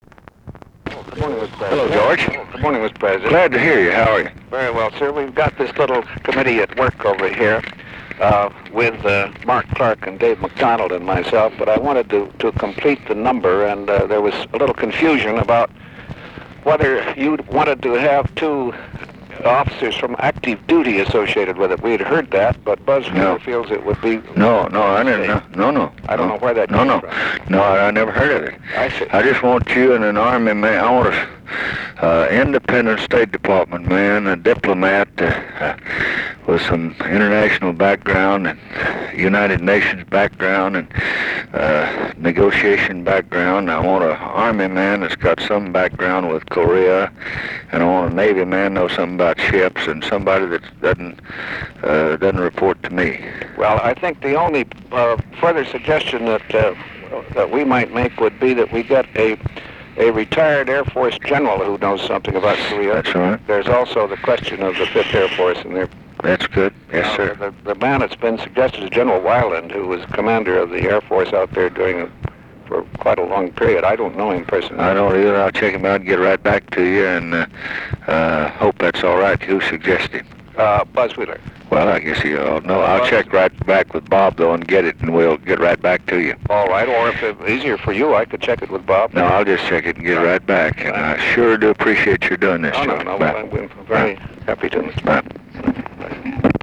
Conversation with GEORGE BALL, February 3, 1968
Secret White House Tapes